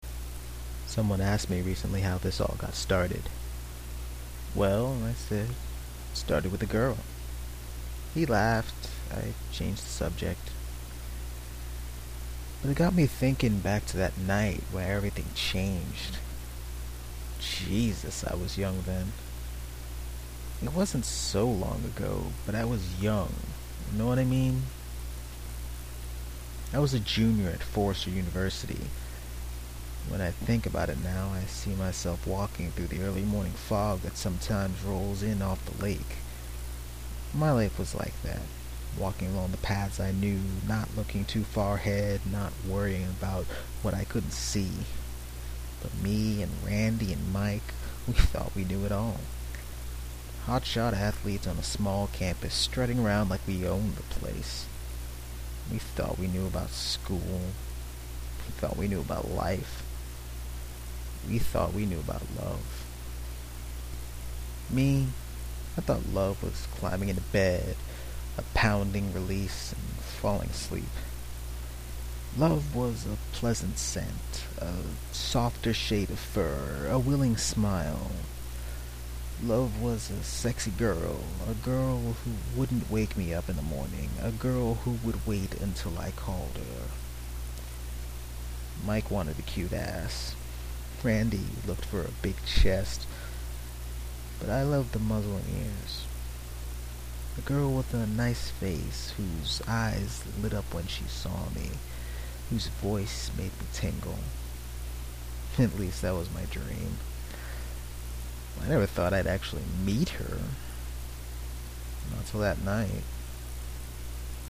This is the introduction, page 3 if you really, really, really need it. And here is an actual bit of voice acting on my part. Dev is repeatedly (and perhaps arousingly) described as having a deep voice, so I dropped mine. I know the quality's not great, I have a cheap headset and a crappy computer.